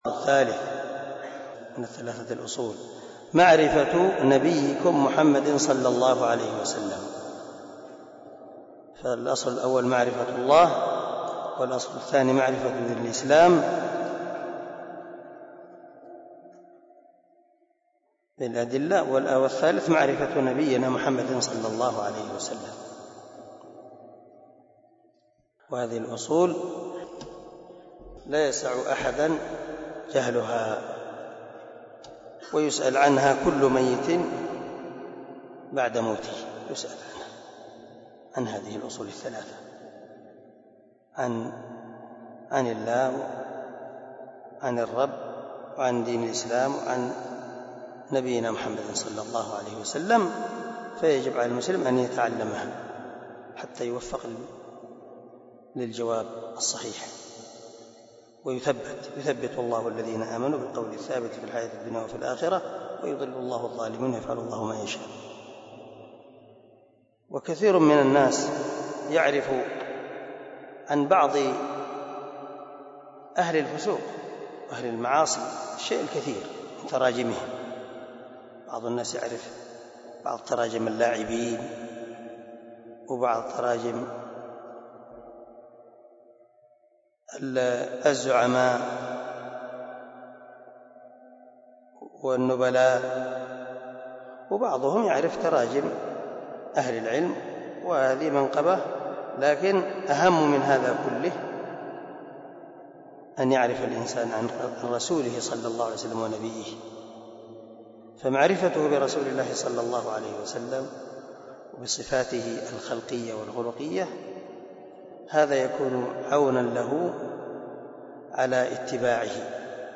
🔊 الدرس 32 من شرح الأصول الثلاثة